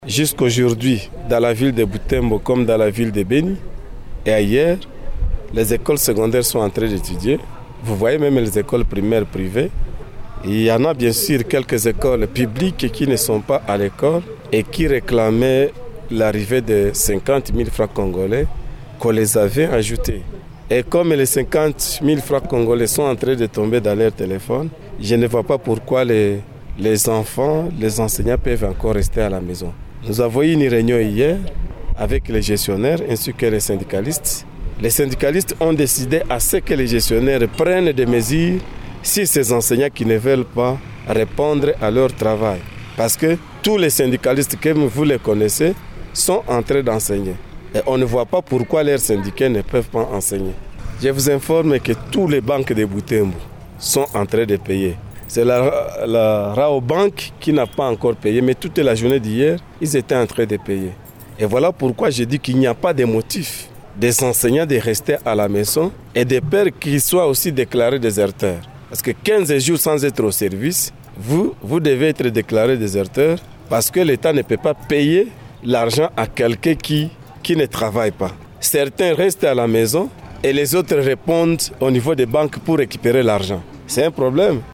Lors d’une déclaration à la presse ce mercredi, ce dernier a assuré que les activités scolaires se déroulent normalement dans la plupart des écoles de son ressort, sauf dans certains établissements publics.